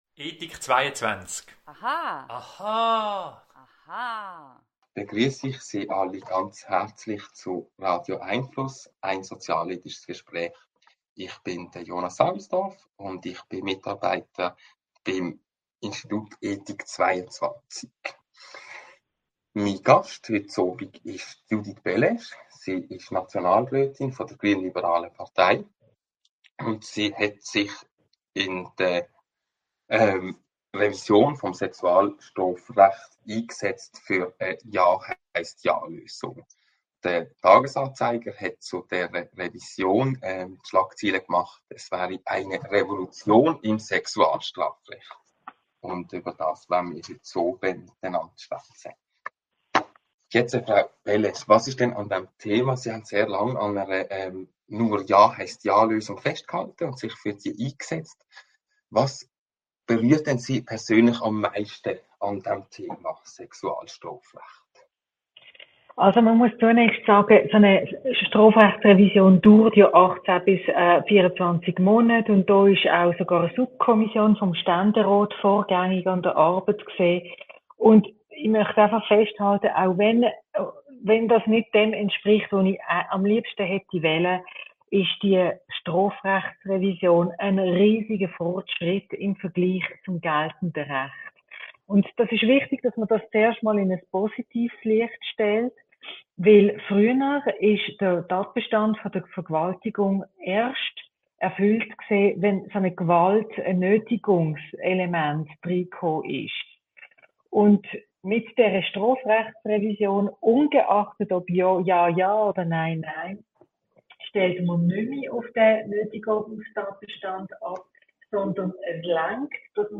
Weitere interessante Überlegungen zum Thema hören Sie im Podcast unseres Gesprächs vom 21. Juni mit Judith Bellaiche, GLP Nationalrätin.